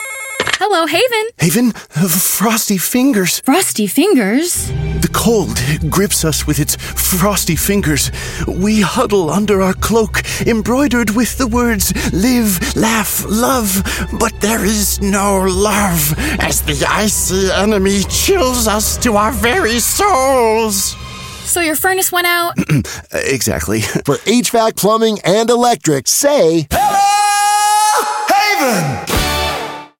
RADIO: "FROSTY FINGERS"